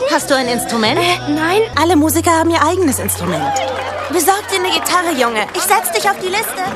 Ein paar kleinere Rollen aus Pixars Coco - Lebendiger als das Leben
Ordnerin des Plaza Talentwettbewerbs